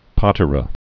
(pätə-rə, pătə-)